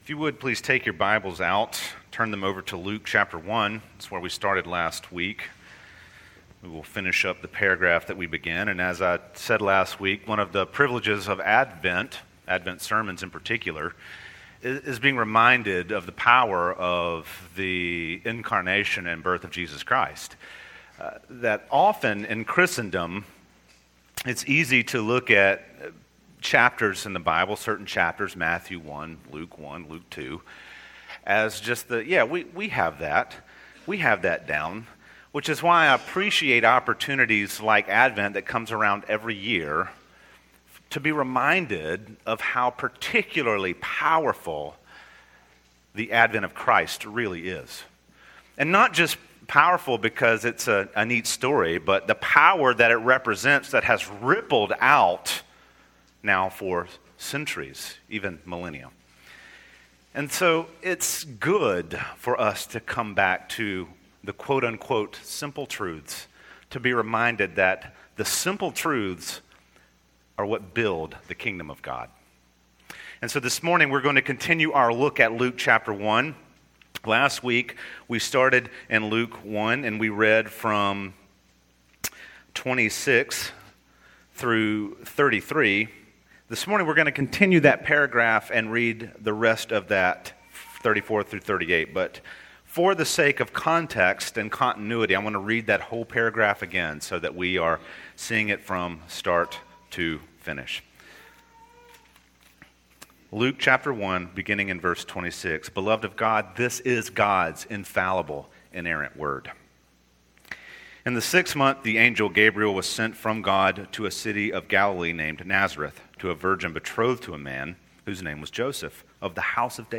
Christmas Topic: Special Occasions Passage